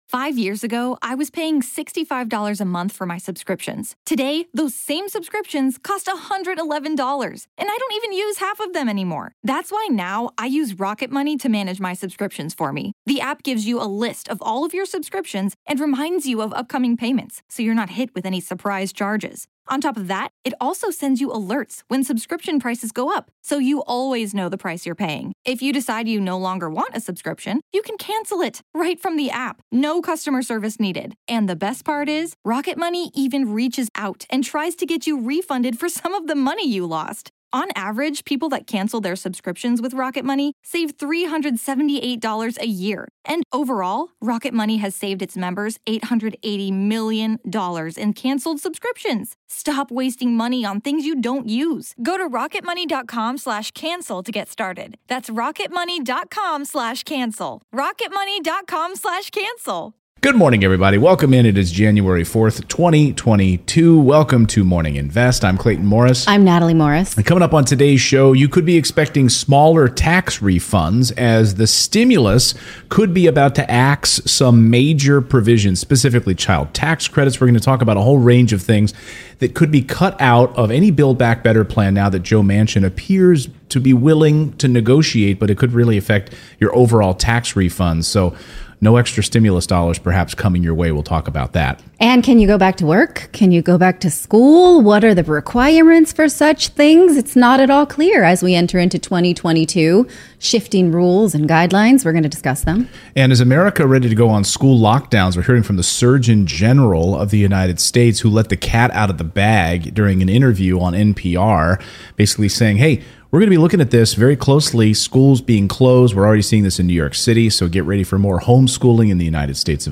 In today's livestream... we're looking at how the changes to the child tax credit and student loan repayments will affect your tax refunds this year.